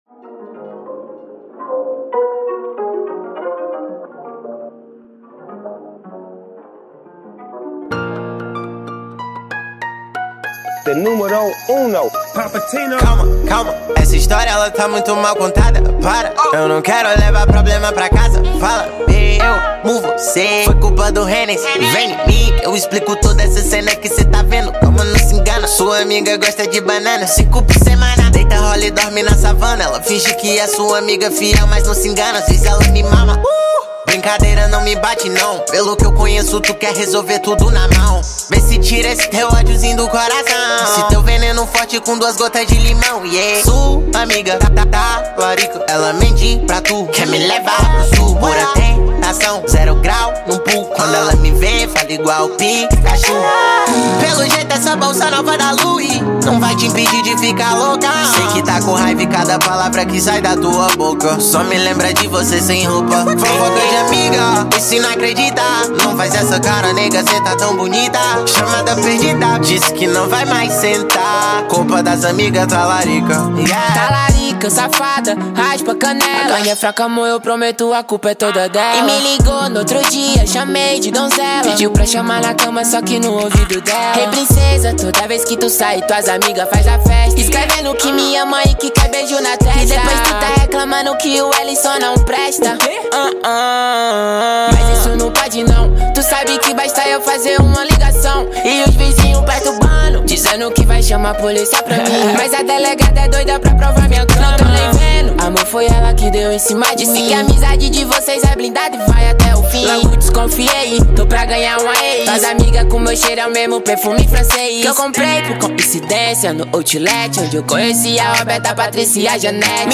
2024-04-08 11:38:11 Gênero: Trap Views